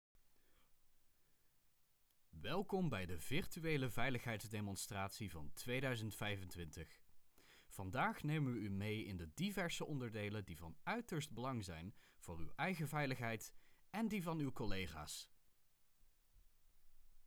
Vanaf het komende fragment, zijn de opnames thuis ingesproken.
-Microfoon = Neumann TLM103 + Shockmount + popfilter
Veiligheid is en zou voor alle bedrijven van de hoogste prioriteit moeten zijn. In dit korte fragment laat ik horen hoe ik door te spelen met intonatie (extra) belangrijke woorden overbreng op de kijker.
veiligheidsvideo bedrijf.wav